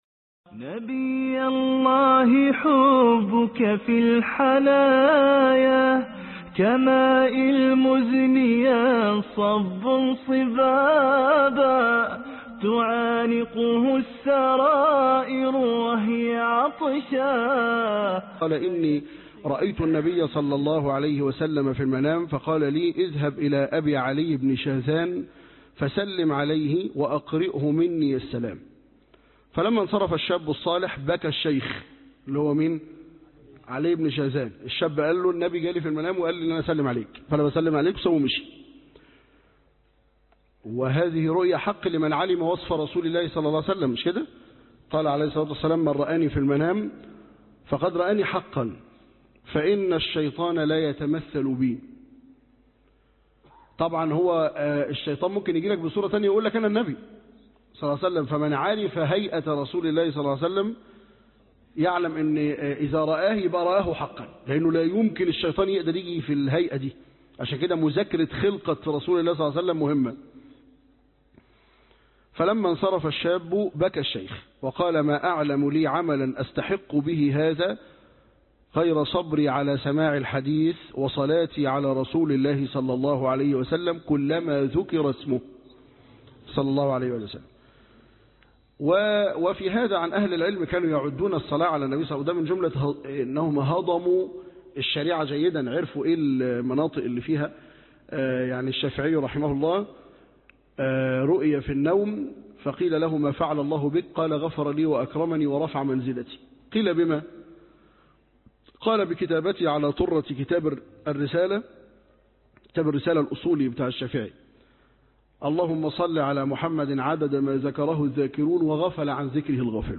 الدرس11(24 ربيع ثان 1433هـ ) السيرة النبوية